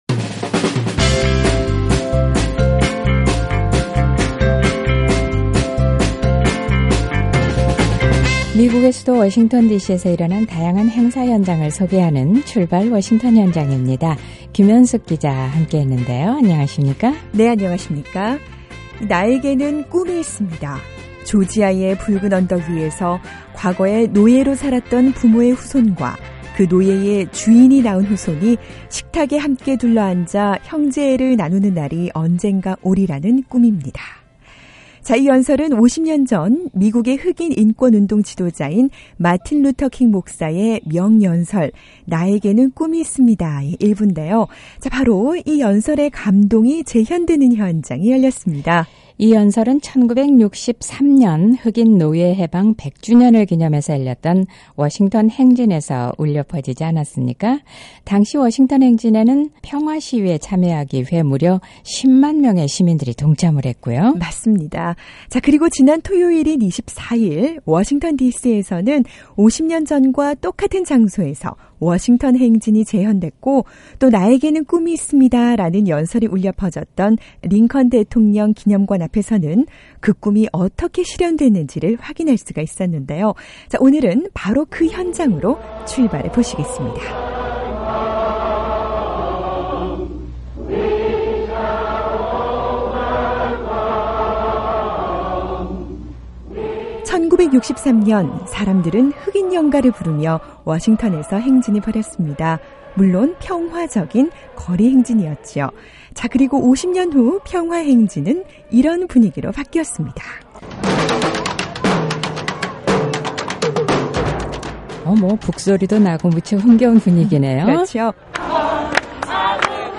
워싱턴 행진 50주년을 맞아 워싱턴 디씨에는 또 다시 수만명이 모여 행진하며, 또 다른 꿈을 나눴는데요. 바로 그 현장으로 출발해 봅니다.